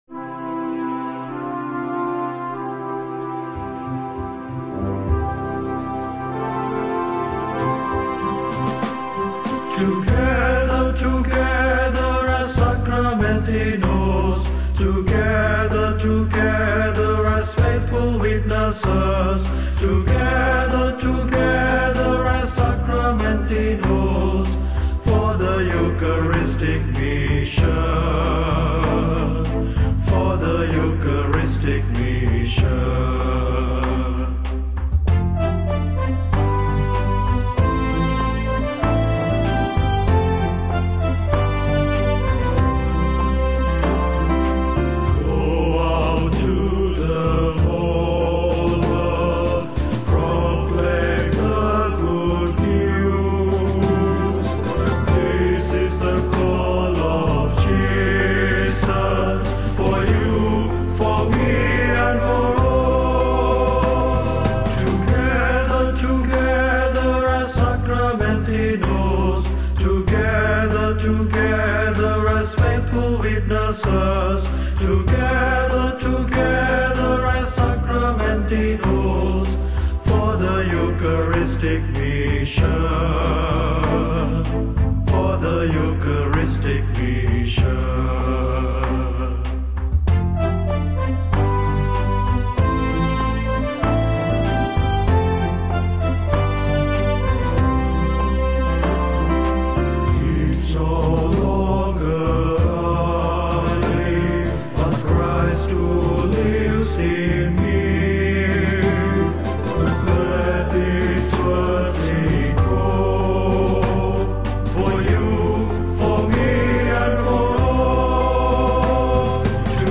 Hymn of the General Chapter
Chapter-Hymn-mp3.mp3